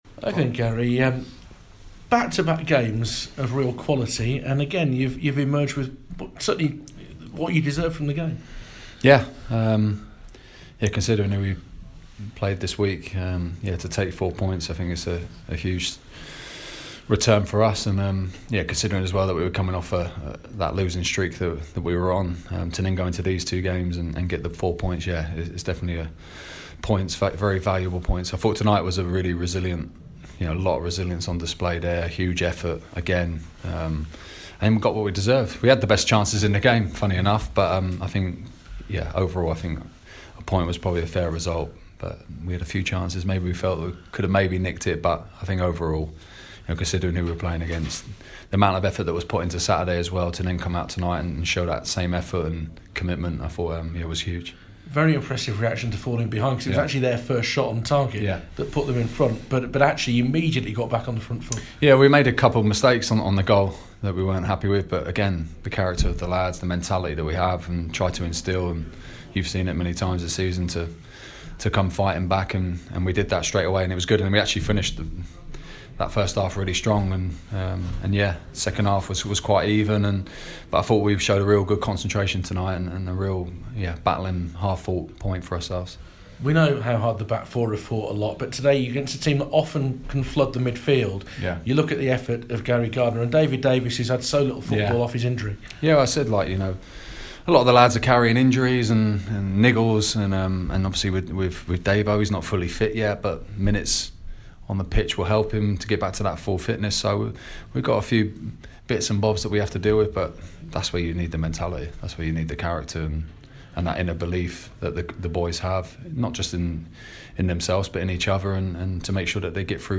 Birmingham City manager Garry Monk tells BBC WM his team were deserving of a point after their 1-1 draw with promotion-chasing Sheffield United at St Andrew's.